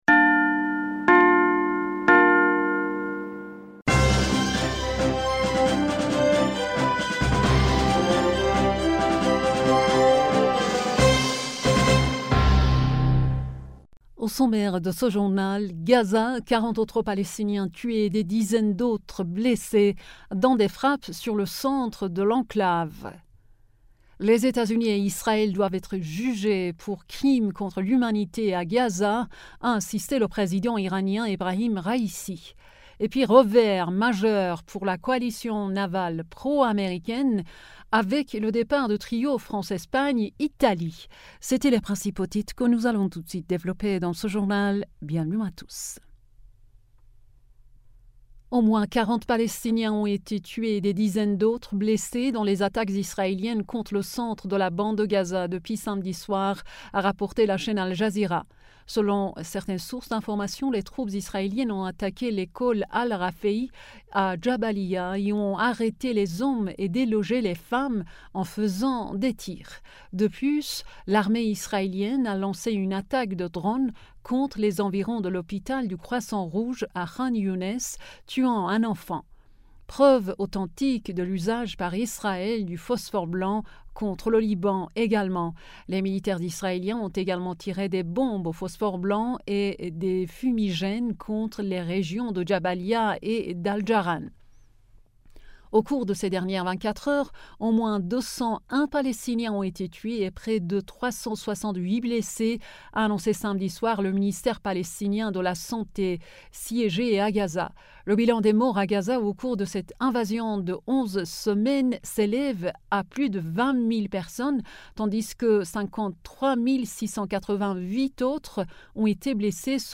Bulletin d'information du 24 Decembre 2023